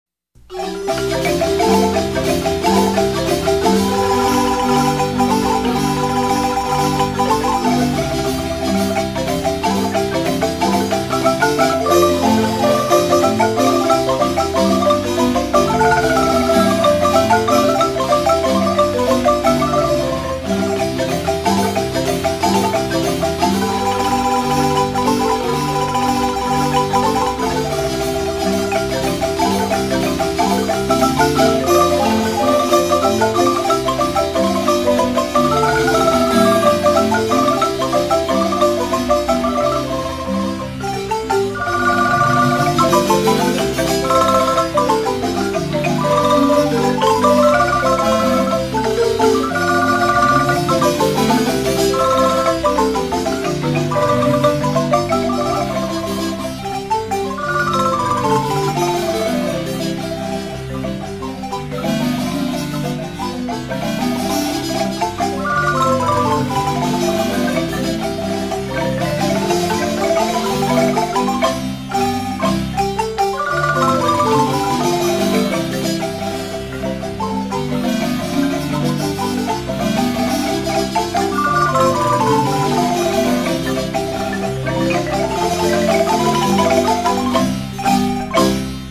parrandera
Música guanacasteca: marimba